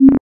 Исправить звук дверей: ... - преобразовать звук в стерео; - уменьшить уровень звука 2024-11-25 09:45:08 +03:00 6.9 KiB Raw History